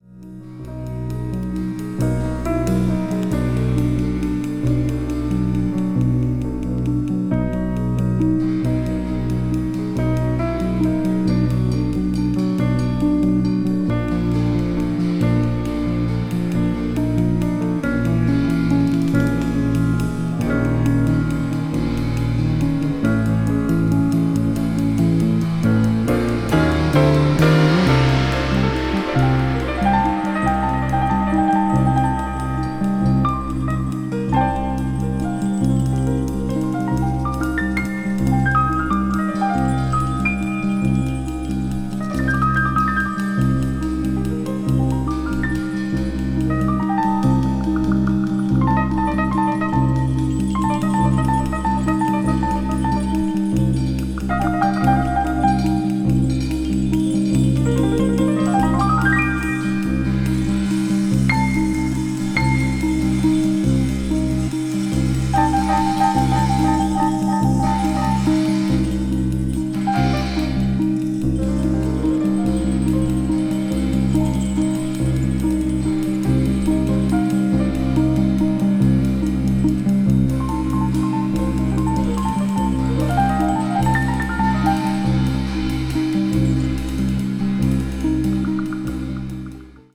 acoustic piano
electric piano
contemporary jazz   crossover   fusion   spritual jazz